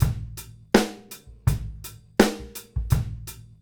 GROOVE 120BR.wav